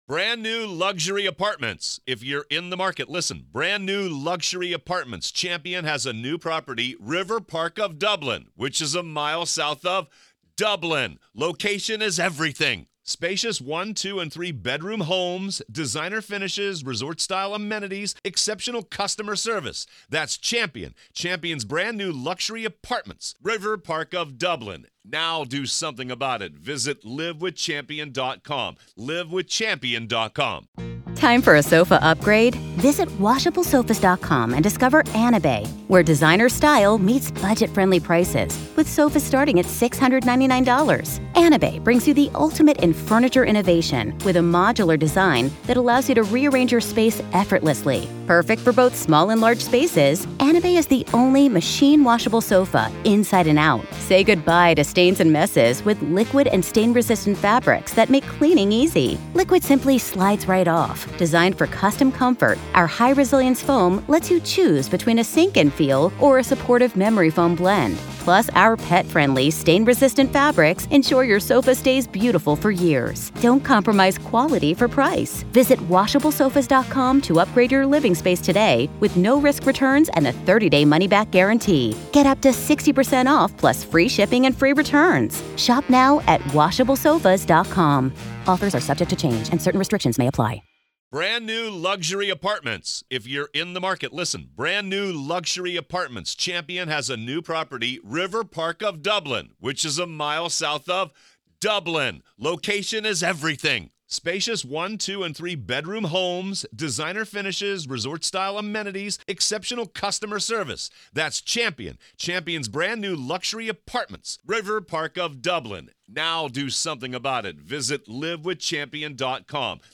The hosts discussed the possibility that Rader might be seeking attention once again.